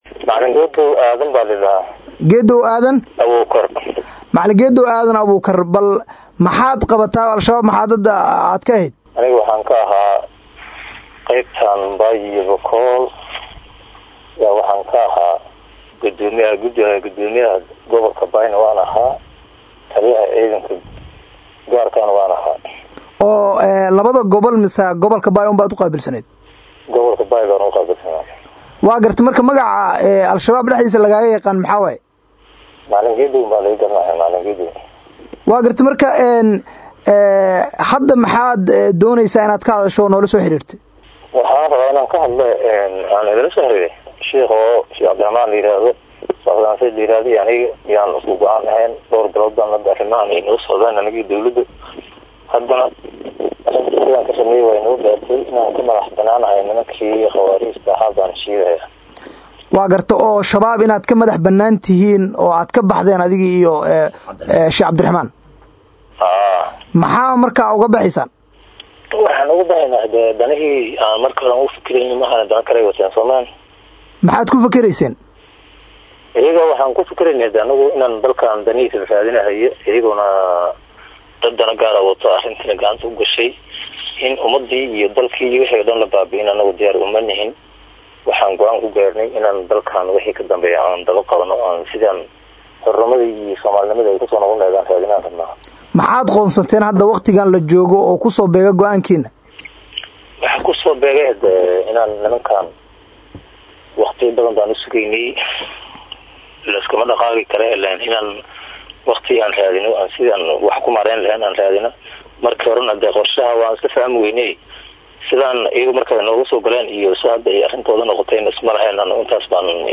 Deg Deg: Horjoogayaal sar sare oo ku dhawaaqay inay ka baxeen Alshabaab “Dhegayso Waraysi”